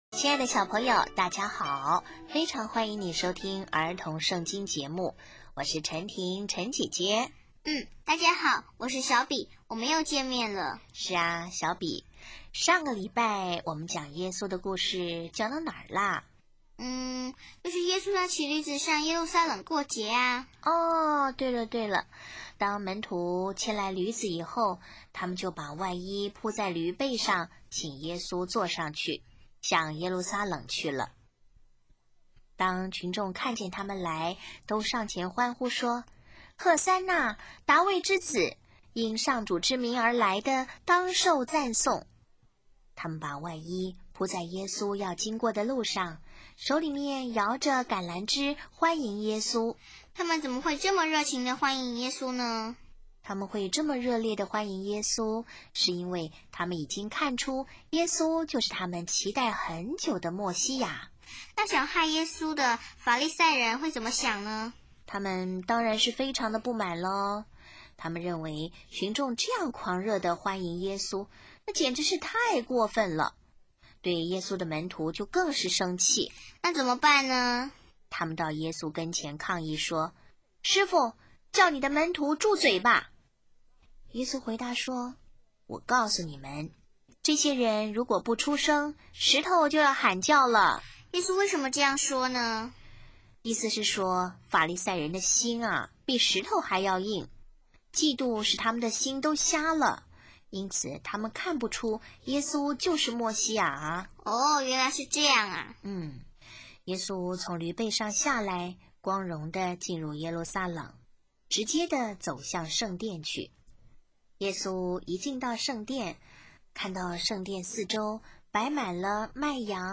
【儿童圣经故事】71|最后晚餐